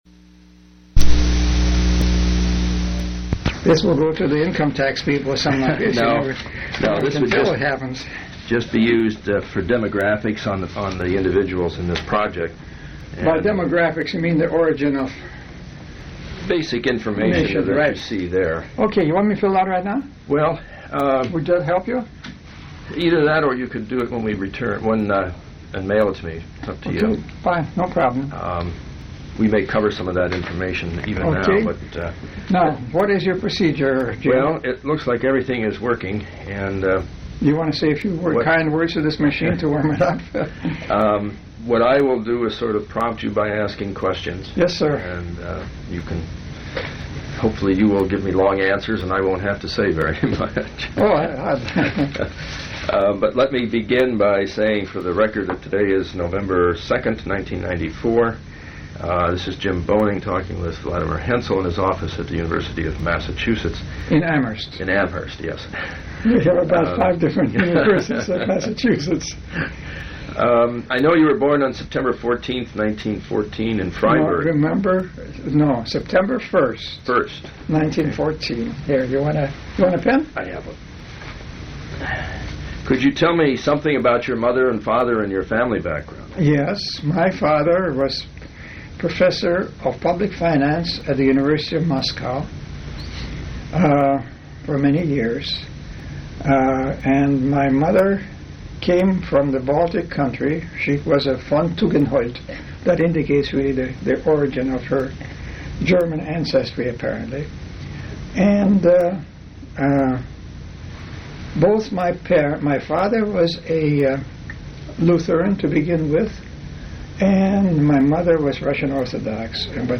Oral history interview with Vladimir Haensel